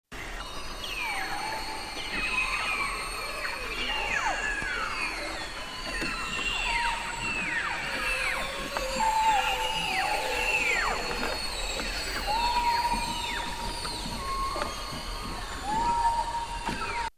Las belugas tienen ese apodo debido a la gran cantidad de sonidos que producen.
Escuche el «canto» de las belugas: